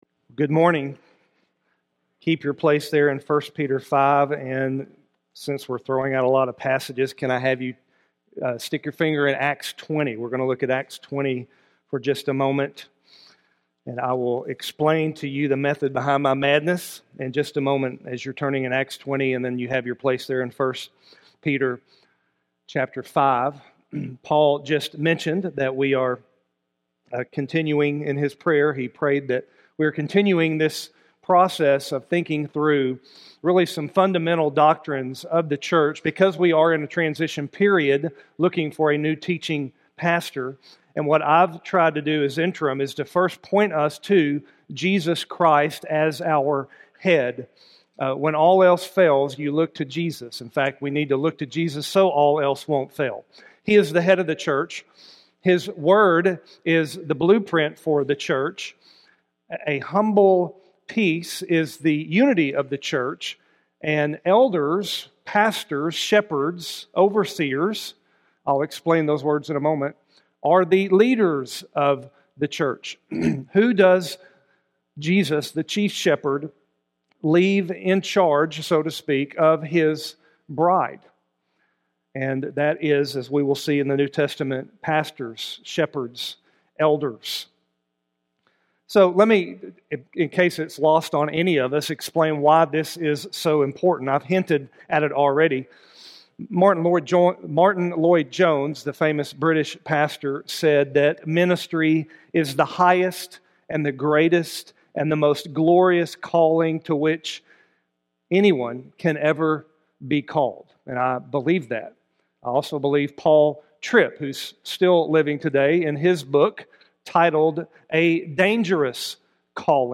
Worship Service, August 30, 2020